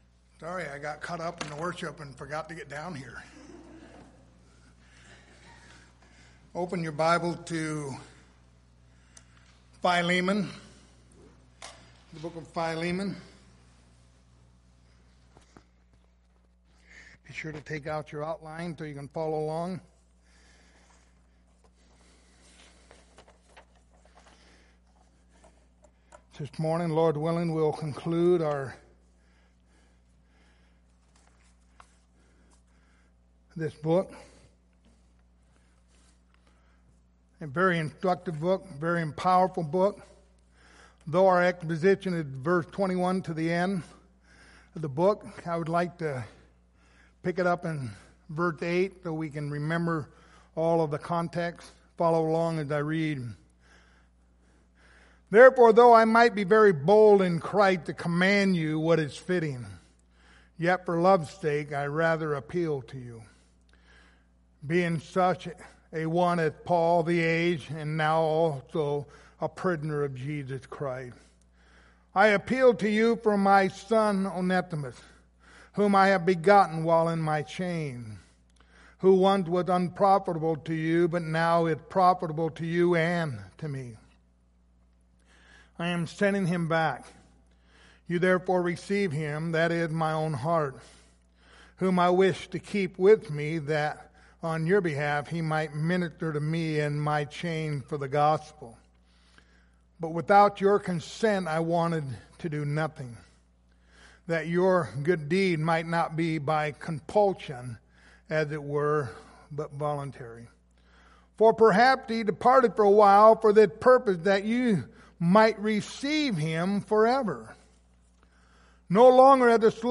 Passage: Philemon 1:21-25 Service Type: Sunday Morning